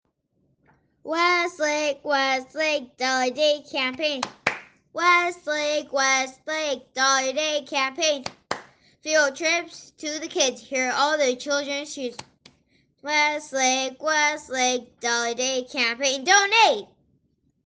Prize Information: English | Arabic | Spanish Listen to our $1/Day Campaign jingle!